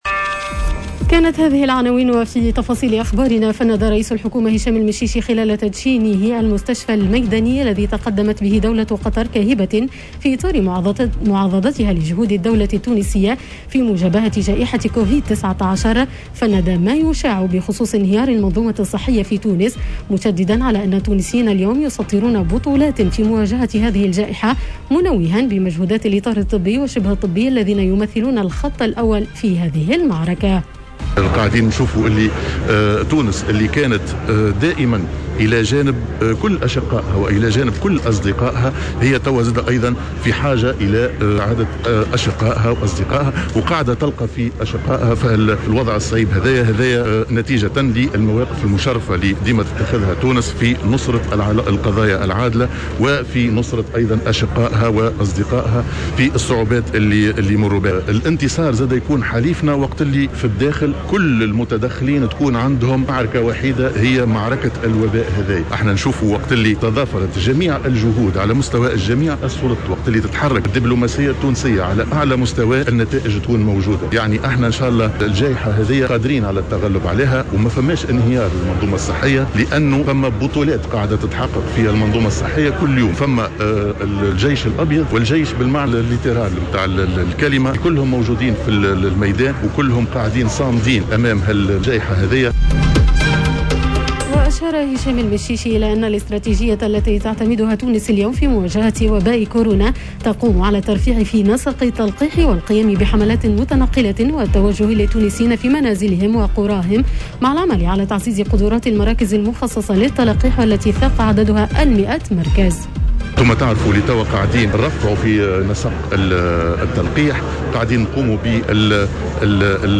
نشرة أخبار السابعة صباحا ليوم الإربعاء 14 جويلية 2021